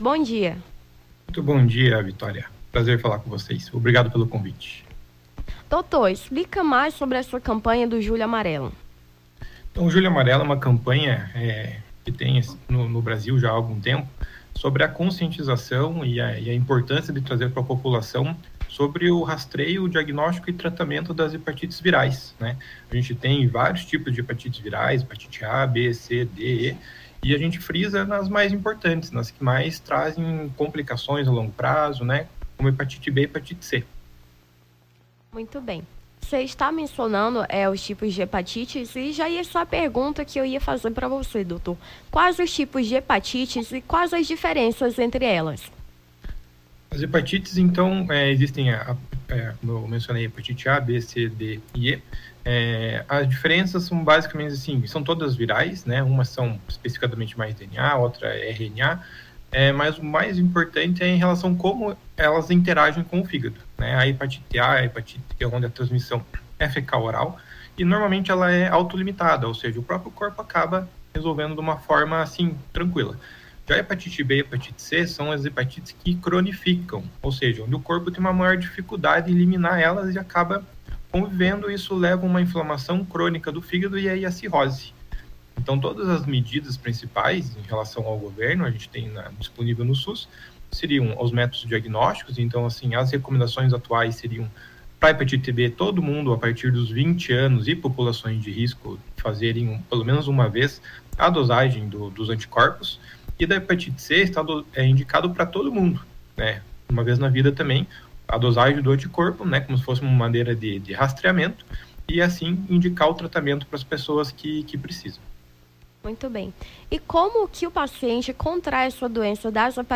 Nome do Artista - CENSURA - ENTREVISTA (HEPATITES VIRAIS) 24-07-23.mp3